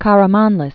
(kärə-mänlĭs, -rä-mänlēs), Konstantinos 1907-1998.